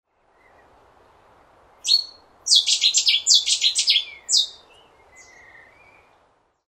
Cetti's Warbler song audible at 750 metre range
On rare, flat calm mornings in Essex on the River Thames, or even with a slight southerly wind, I've heard Cetti's Warbler singing from the Kent side of the river, a distance of at least 750 metres to the nearest bush.